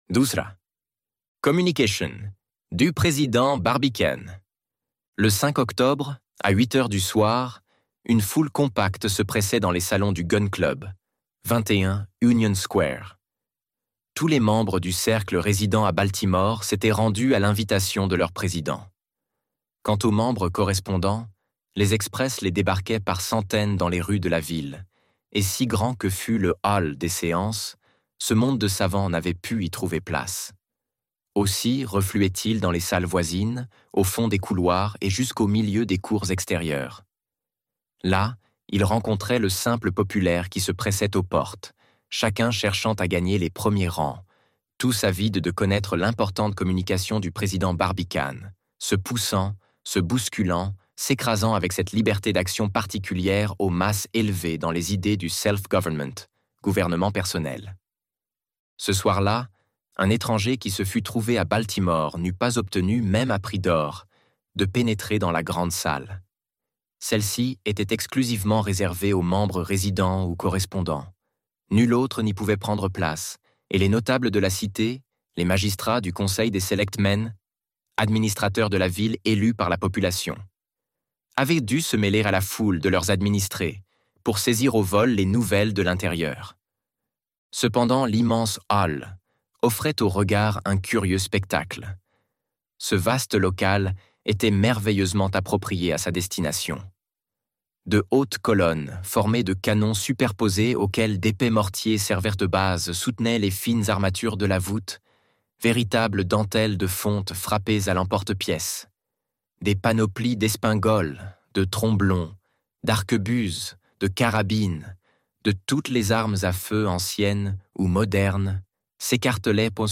De la Terre à la Lune - Livre Audio